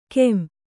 ♪ kem / ken / keṇ